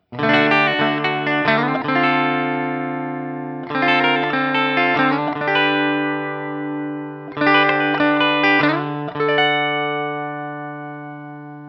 This guitar has a very bright Stratty-like tone that’s made powerful through the EMG humbucker.
Open Chords #1
I recorded this guitar using my Axe-FX II XL+, direct into my Macbook Pro using Audacity.
Since there is only one pickup and thus no pickup selector switch, the recordings are each of the one pickup with the knobs on 10.